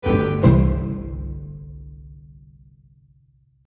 fail.mp3